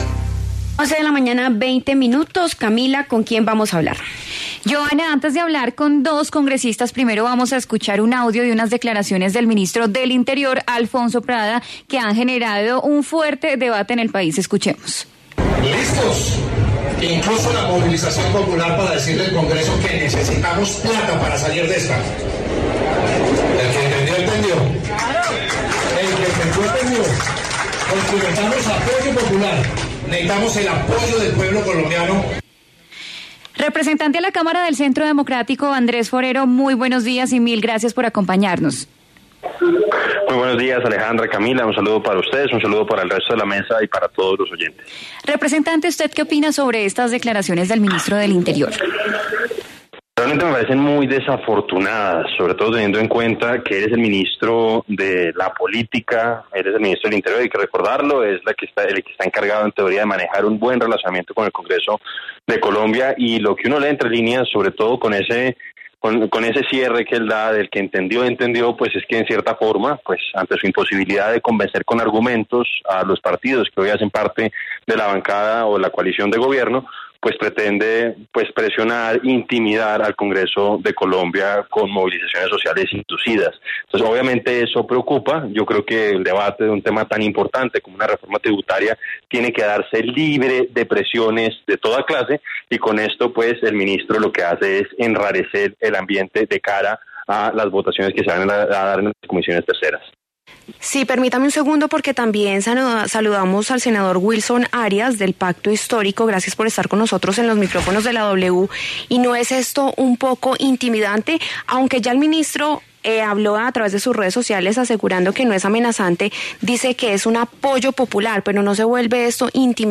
En La W hablaron los congresistas Andrés Forero, del Centro Democrático, y Wilson Arias, del Pacto Histórico.